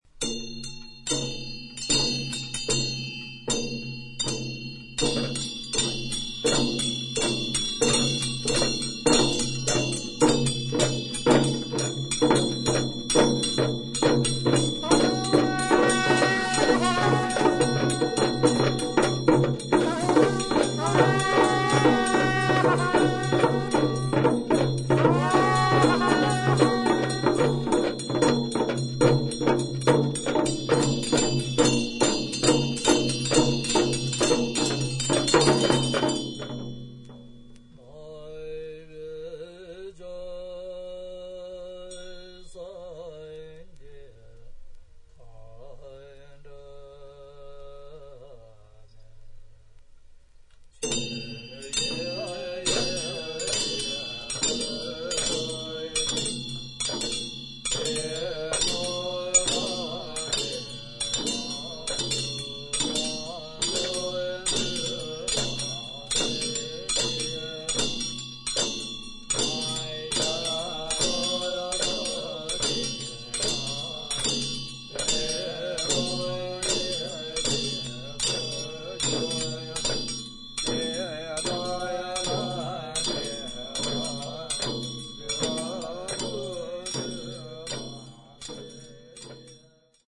インド北部/ヒマーチャル・プラデーシュ州にて録音。肺結核を病んでいる一人のチベット人の為に、一日中行われた悪霊払いの儀式を生々しくレコーディングした作品です。